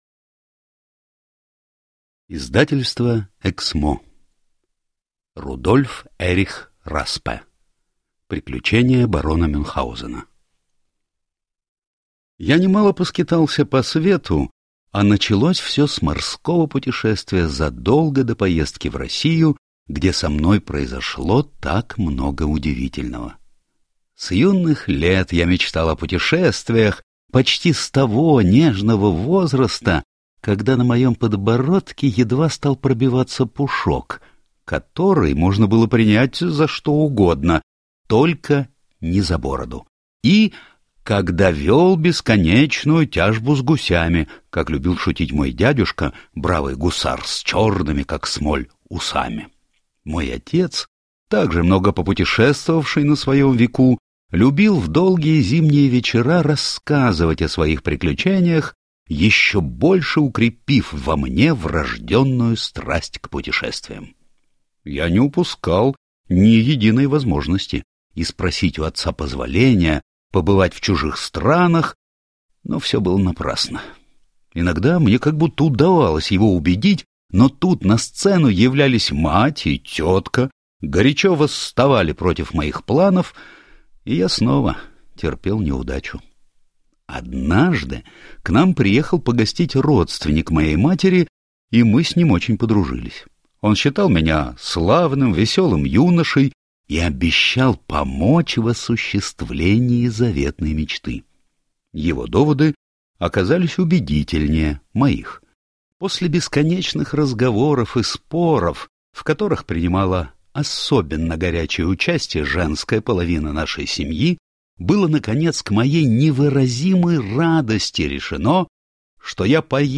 Аудиокнига Приключения барона Мюнхгаузена | Библиотека аудиокниг